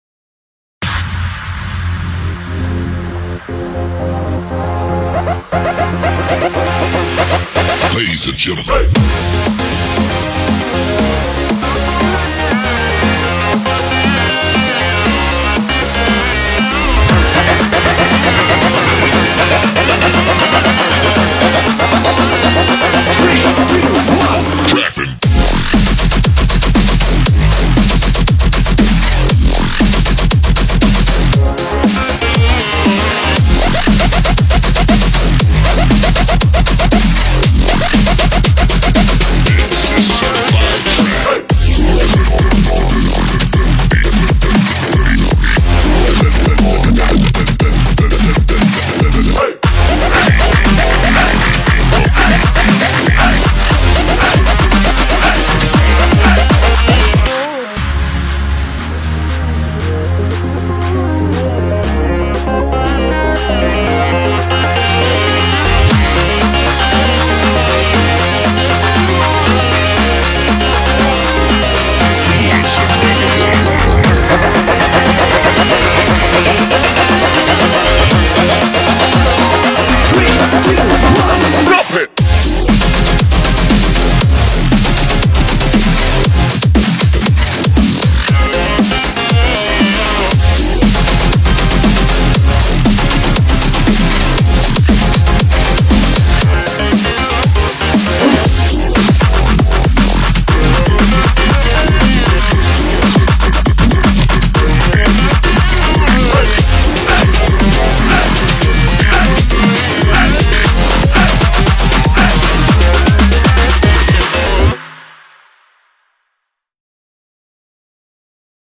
BPM118
Audio QualityPerfect (Low Quality)